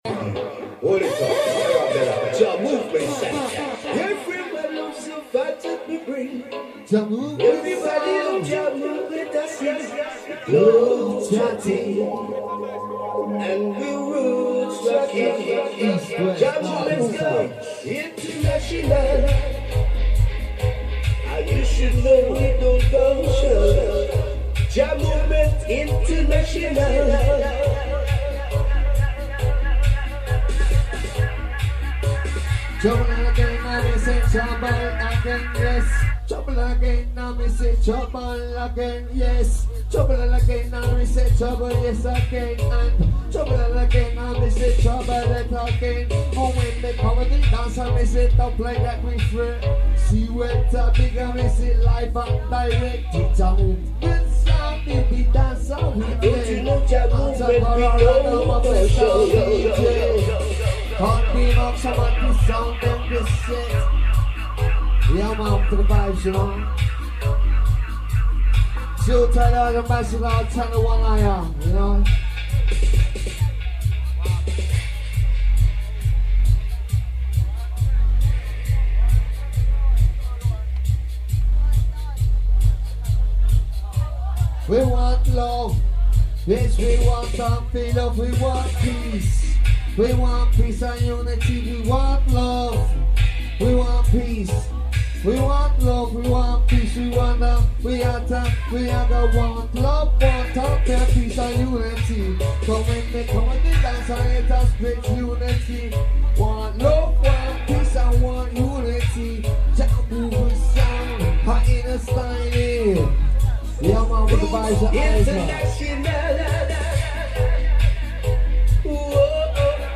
Royal Hotel Southend